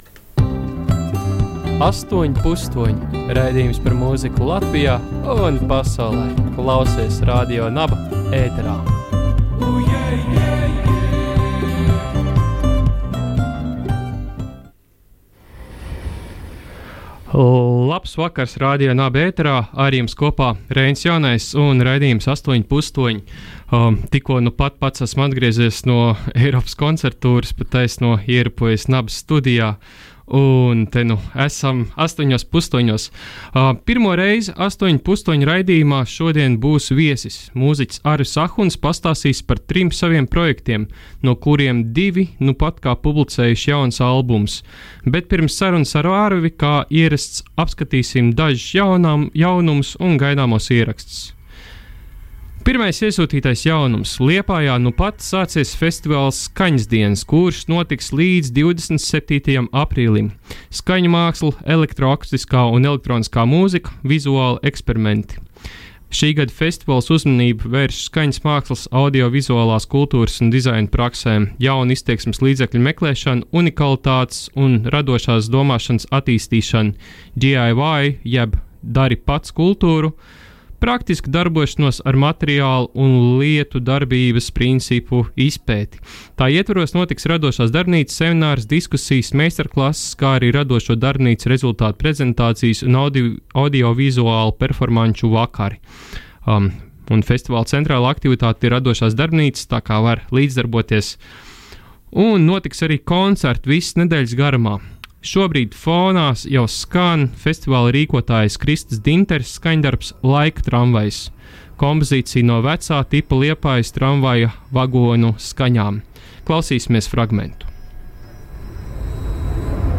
Pirmo reizi ''Astoņi pustoņi'' raidījumā būs viesis.